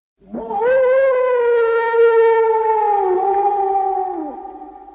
wolf howling sound effect 1.ogg
Original creative-commons licensed sounds for DJ's and music producers, recorded with high quality studio microphones.
[wolf-howling-sound-effect]_oob.mp3